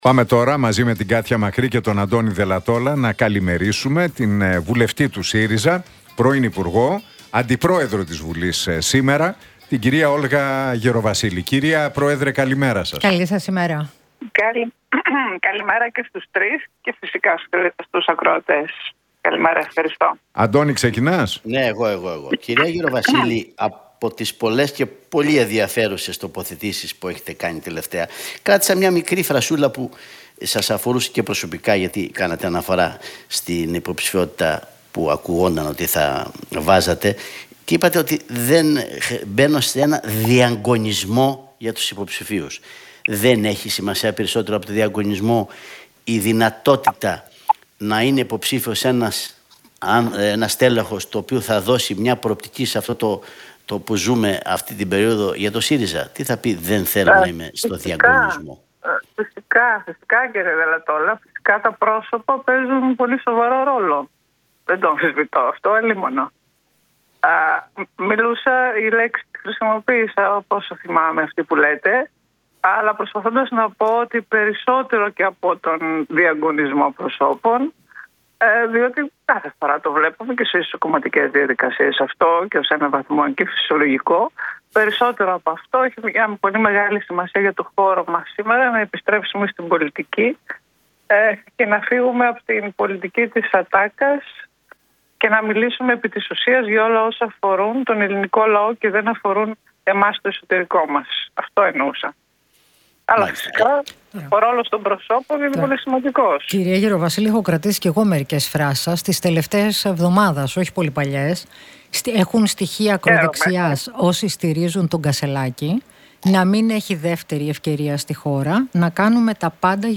μίλησε η βουλευτής του ΣΥΡΙΖΑ, Όλγα Γεροβασίλη
από τη συχνότητα του Realfm 97,8